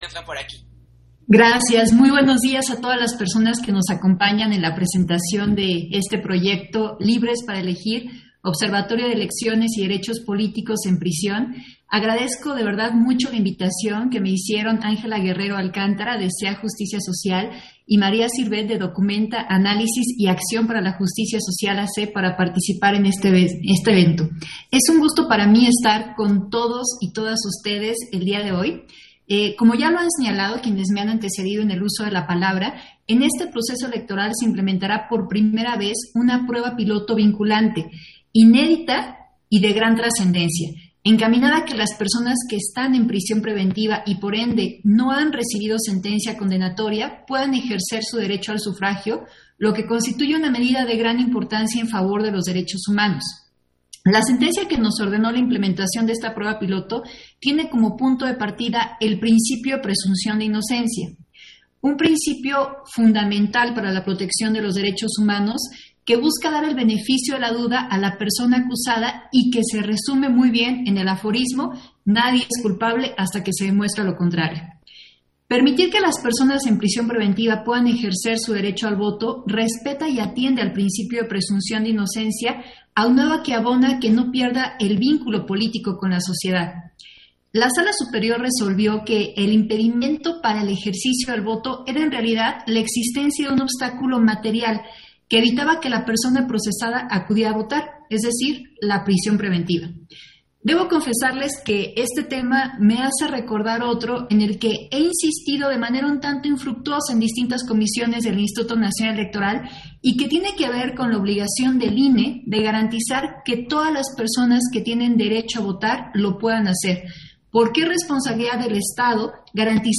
Intervención de Dania Ravel, en la presentación de Libres para Elegir, Observatorio de elecciones y derechos políticos en prisión